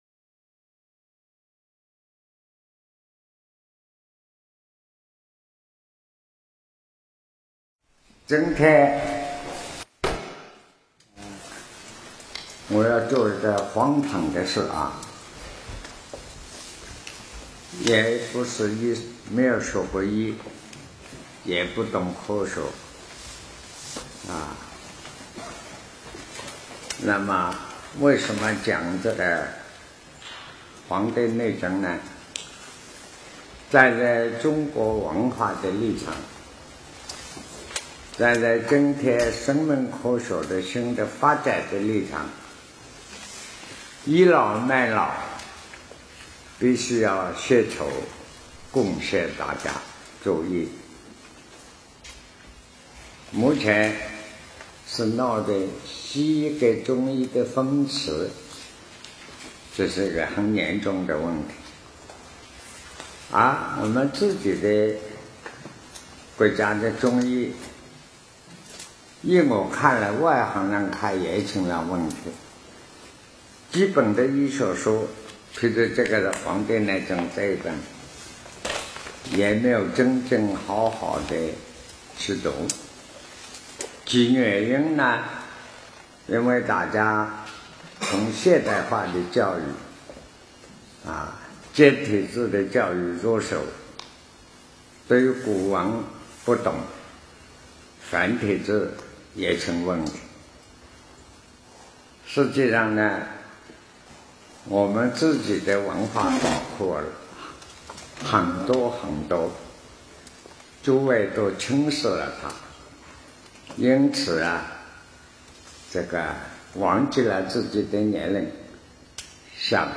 南怀瑾先生所讲《生命科学与黄帝内经》辅导学习课程